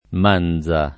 manza
[ m # n z a ]